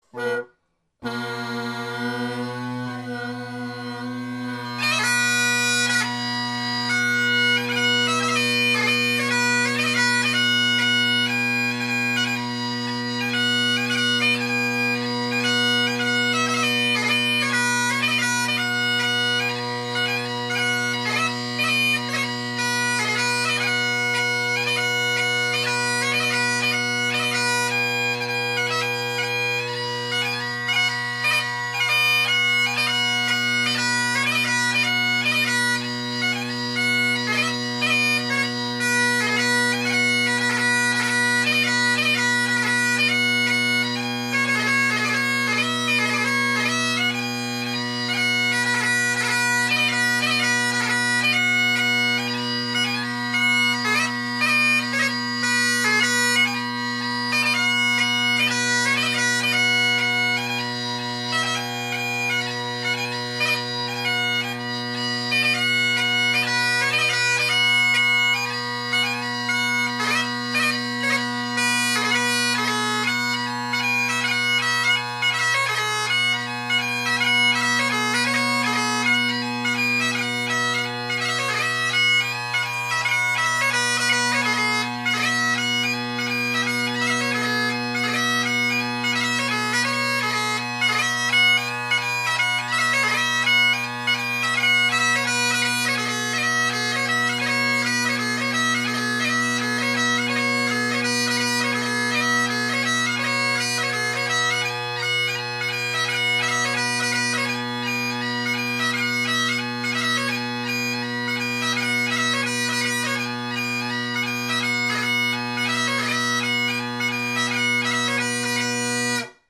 Great Highland Bagpipe Solo